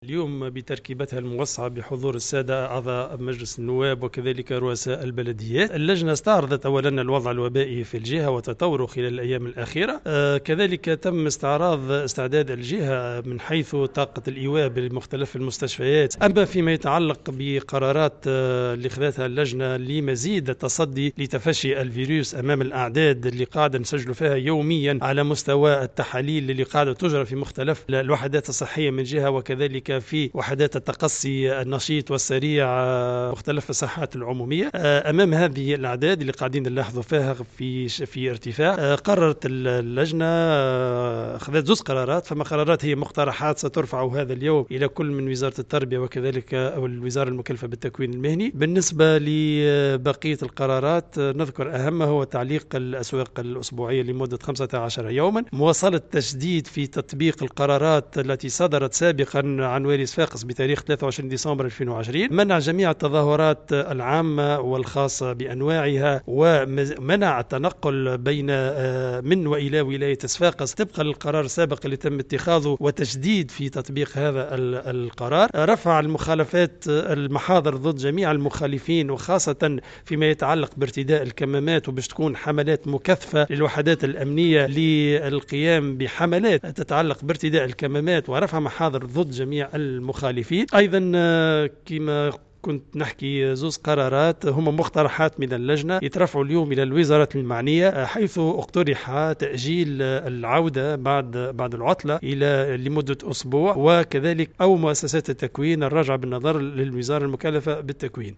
أكد والي صفاقس أنيس الوسلاتي في تصريح لمراسل "الجوهرة أف أم" بأن لجنة مجابهة الكوارث وتنظيم النجدة بصفاقس اتخذت مجموعة من القرارات على اثر اجتماع انعقد عشية اليوم الاثنين للنظر في اجراءات التصدي لانتشار فيروس كورونا في ظل تصاعد اعداد الاصابات.